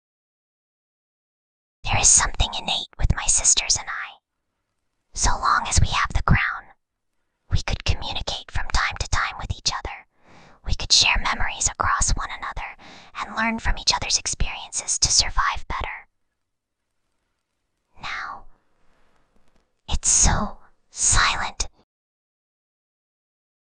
Whispering_Girl_28.mp3